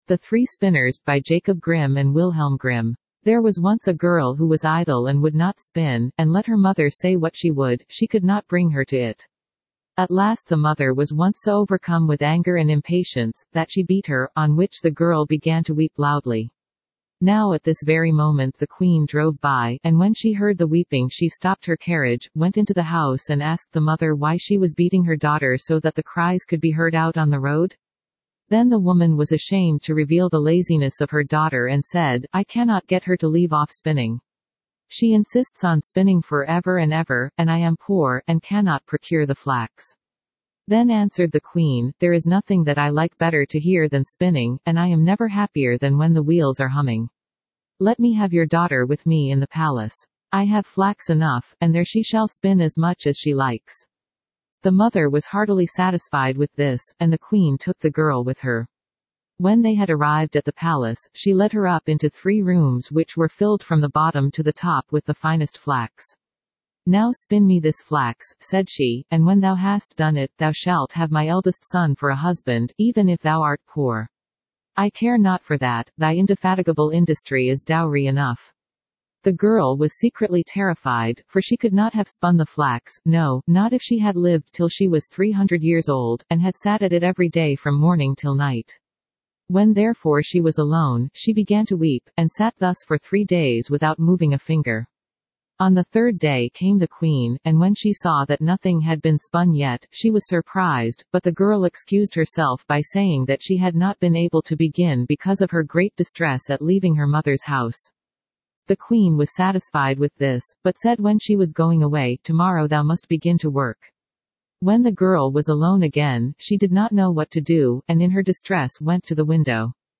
Standard (Female)